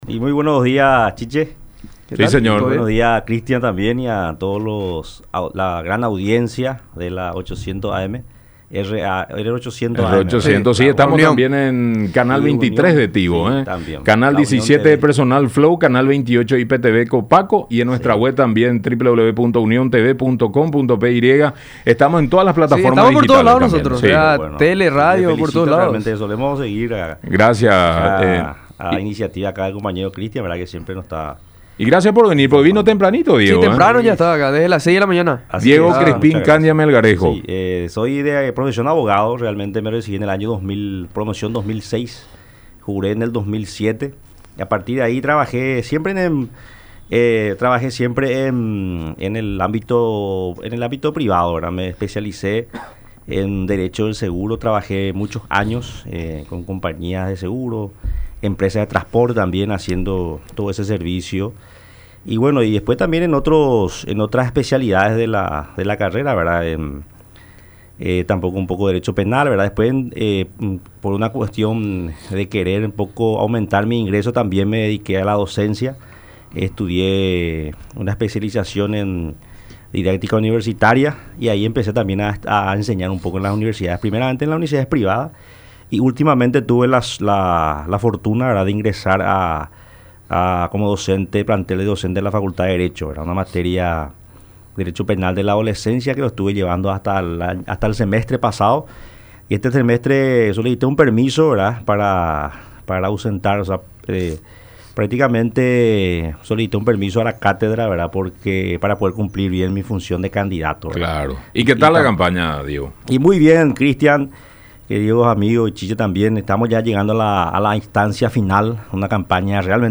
en su visita a los estudios de Unión TV y radio La Unión durante el programa La Mañana De Unión